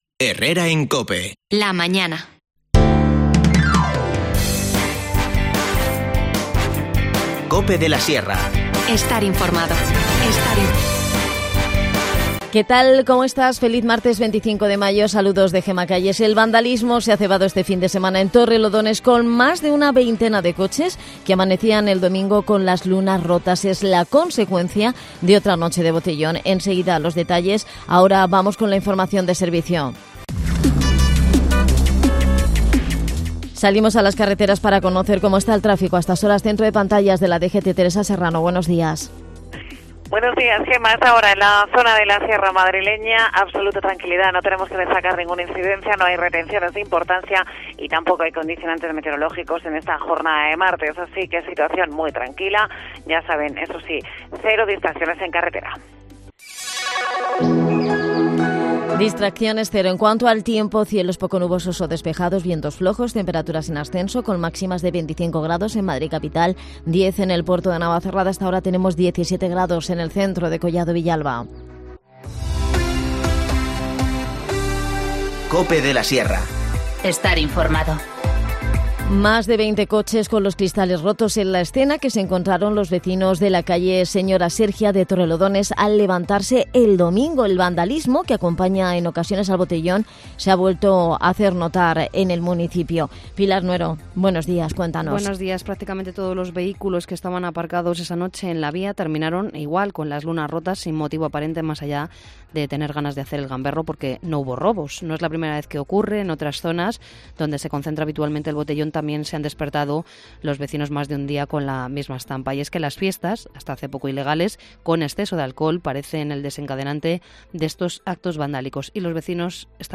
Hablamos